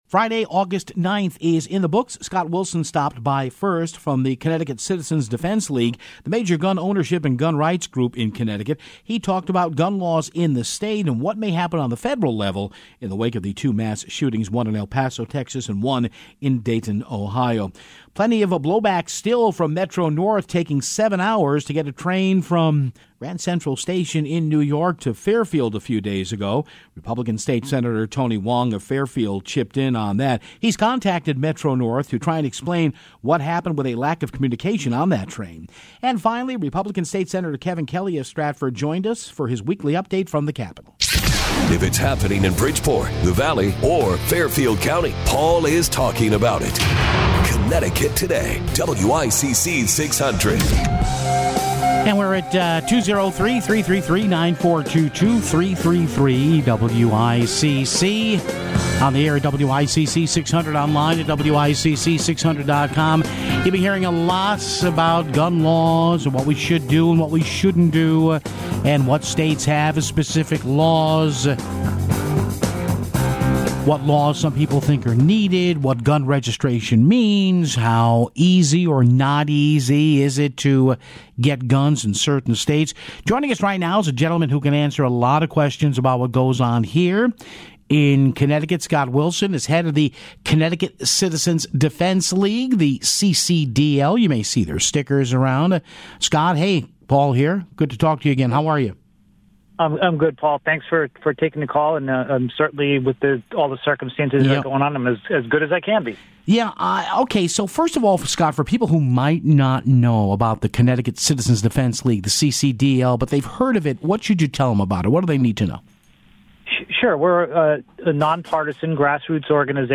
Then, State Sen. Tony Hwang called in to talk about the reaching out to Metro North following this past weekend's transit debacle. And finally, State Sen. Kevin Kelly joined the show for his weekly spot.